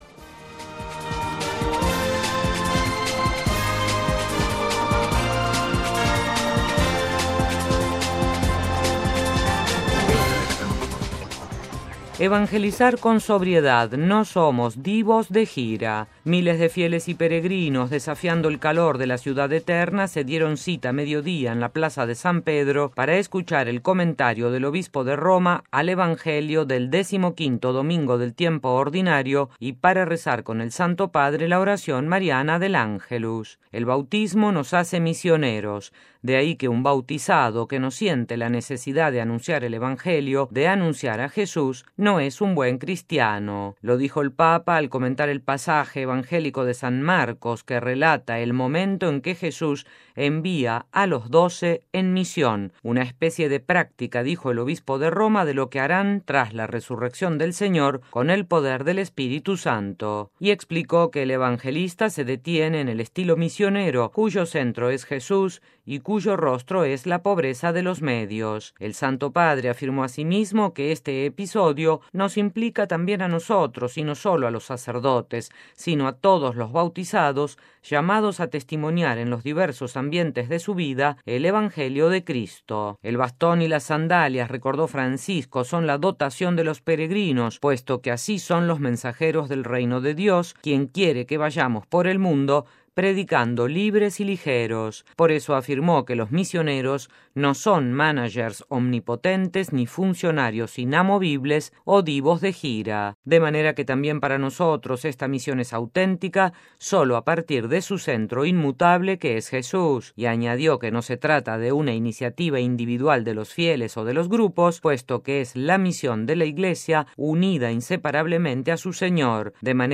Miles de fieles y peregrinos, desafiando el calor de la Ciudad Eterna, se dieron cita a mediodía en la Plaza de San Pedro para escuchar el comentario del Obispo de Roma al Evangelio del este XV domingo del tiempo ordinario y para rezar con el Santo Padre la oración mariana del Ángelus